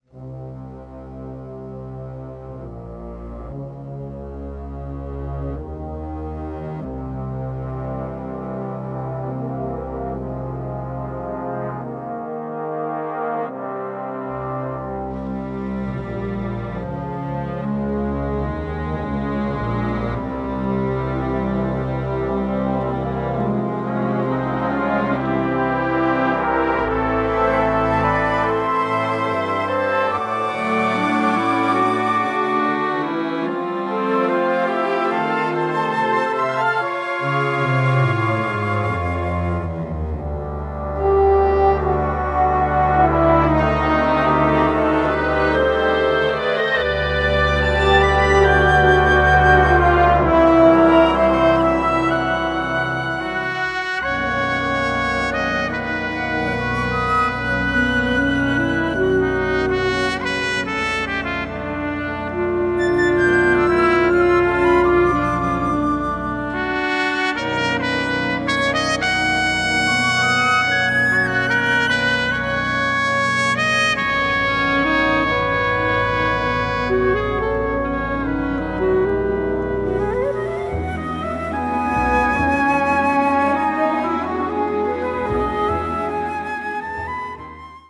for Concert Band